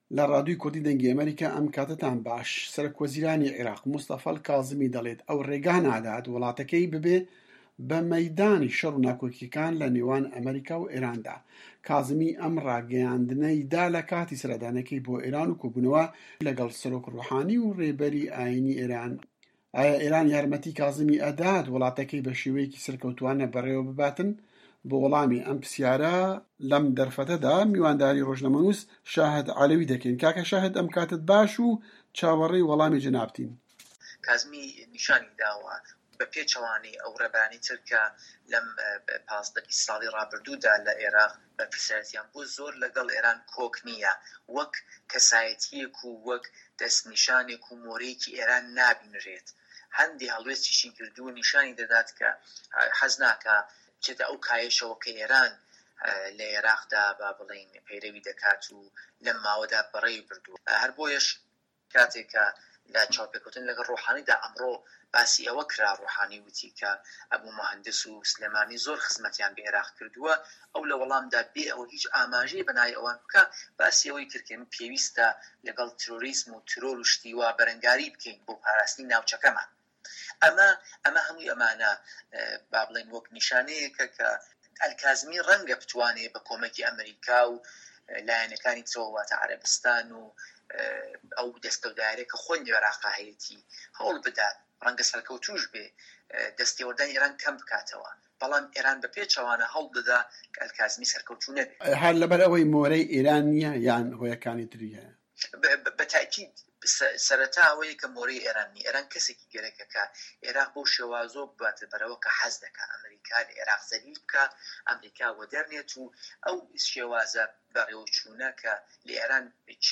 دەقی وتووێژەکە: